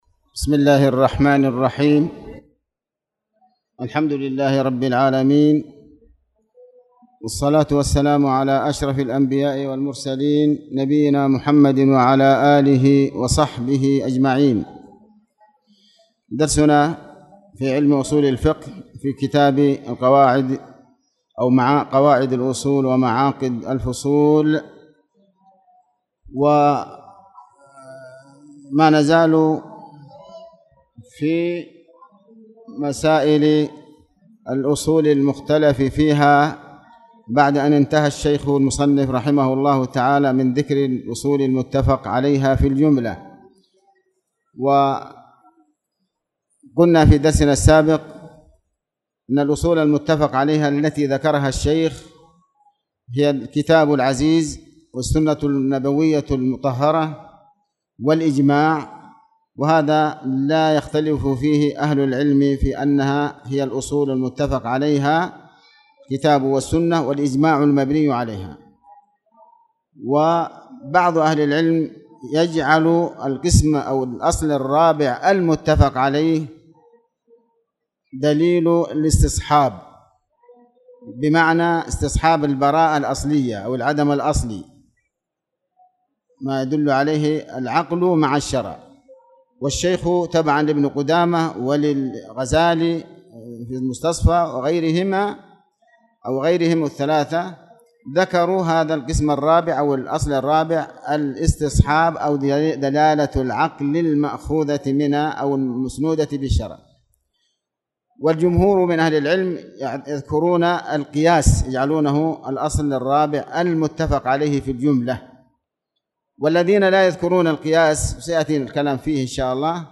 تاريخ النشر ٢٢ شوال ١٤٣٧ هـ المكان: المسجد الحرام الشيخ: علي بن عباس الحكمي علي بن عباس الحكمي قول الصحابي